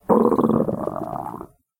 Compresses and normalizes vore sounds 2021-07-18 06:21:01 +00:00 17 KiB Raw History
growl5.ogg